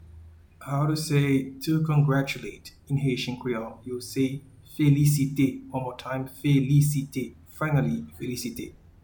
Pronunciation:
to-Congratulate-in-Haitian-Creole-Felisite.mp3